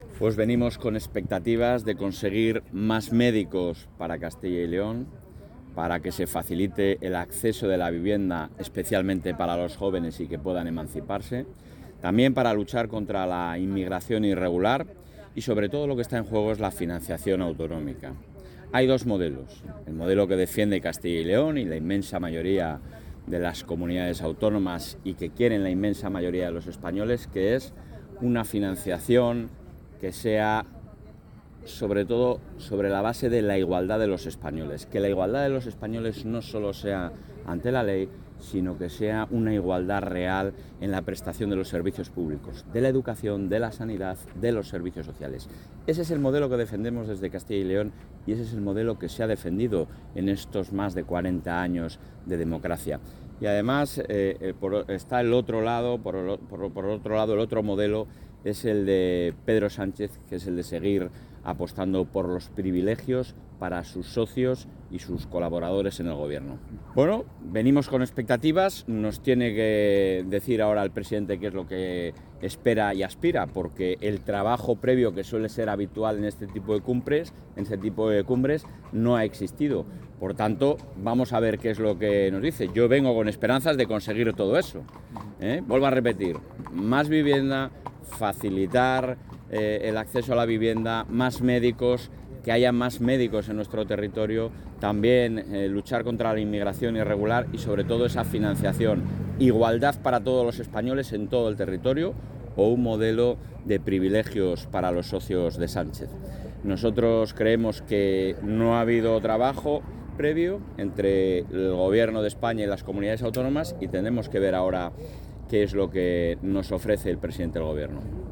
Declaraciones del presidente de la Junta a su llegada a la XXVII Conferencia de Presidentes | Comunicación | Junta de Castilla y León
El presidente de la Junta de Castilla y León, Alfonso Fernández Mañueco, participa hoy en la XXVII Conferencia de Presidentes, que se celebra en Santander. A su llegada al Palacio de la Magdalena, lugar elegido para la celebración del cónclave de mandatarios autonómicos, ha enumerado los asuntos de interés sobre los que la Comunidad demanda soluciones.